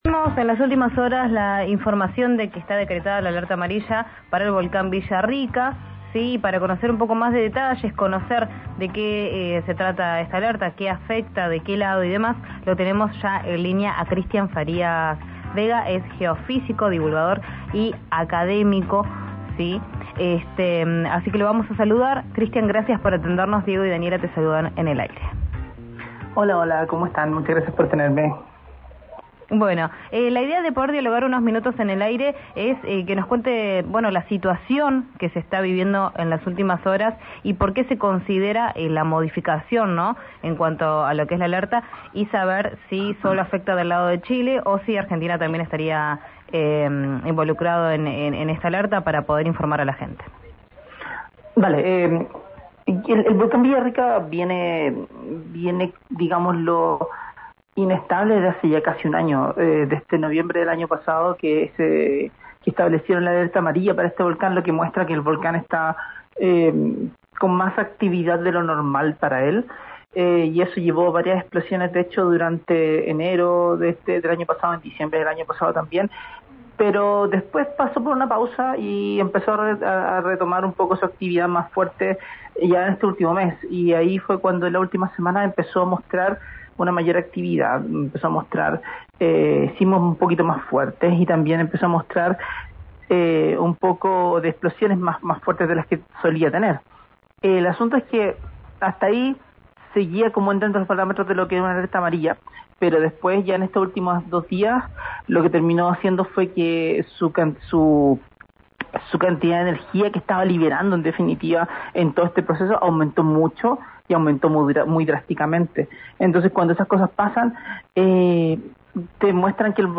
Escuchá al geofísico